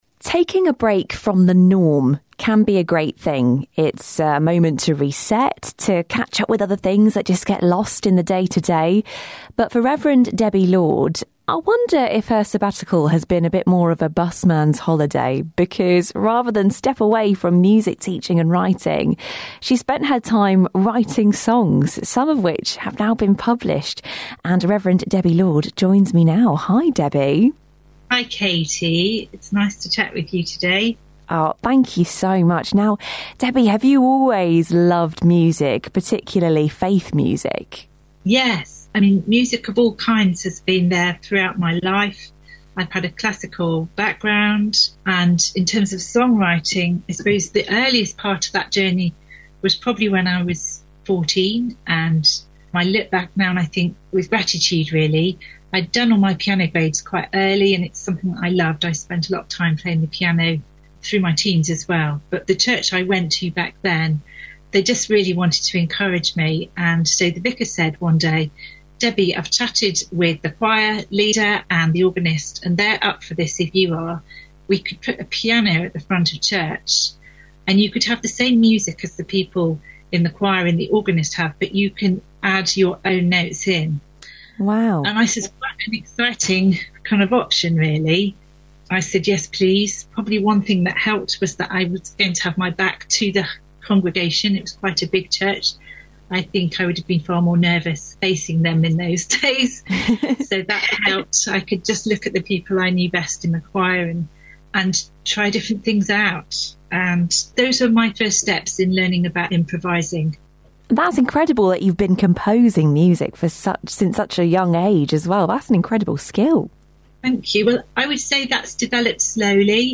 BBC Radio Oxford interview: